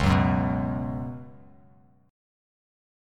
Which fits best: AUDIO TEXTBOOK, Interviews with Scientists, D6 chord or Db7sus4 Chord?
Db7sus4 Chord